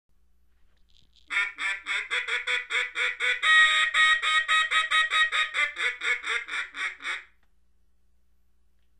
Pop Up Call The Pop Up Call is used after the feeding call when the ducks are not landing and pulling away from your decoy spread. It is the sound ducks use to get the attention of the ducks because they did not land, then throw a comeback call at them to bring them back.